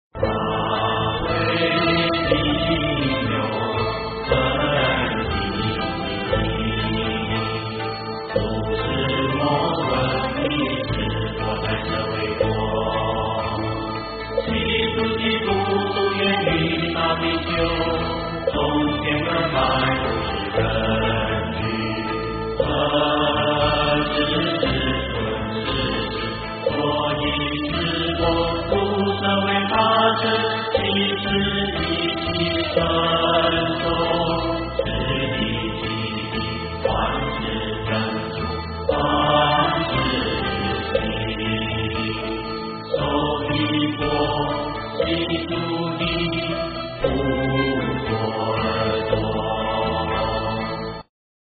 金刚经-法会因由分第一 诵经 金刚经-法会因由分第一--未知 点我： 标签: 佛音 诵经 佛教音乐 返回列表 上一篇： 梵网经-十长养心 下一篇： 金刚经-妙行无住分第四 相关文章 药师经 药师经--无尽灯楼...